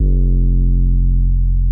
808s
Saint 808.wav